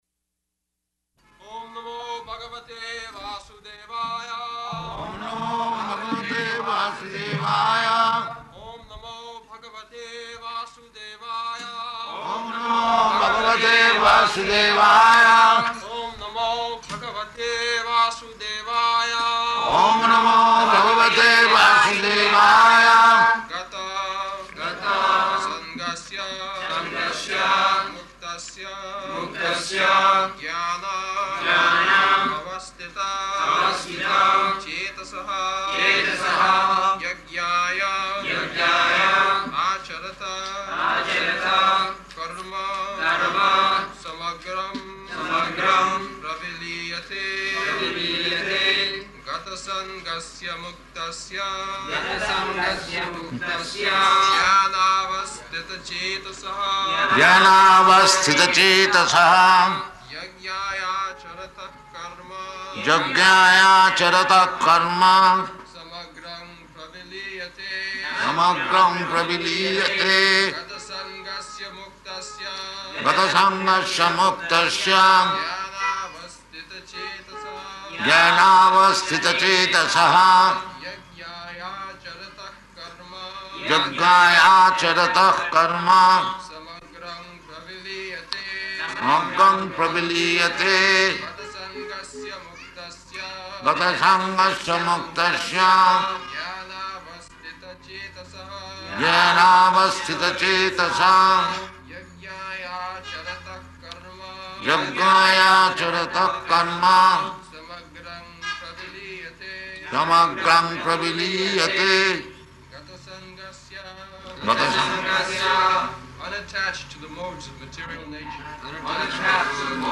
April 12th 1974 Location: Bombay Audio file
[Prabhupāda and devotees repeat] [leads chanting of verse]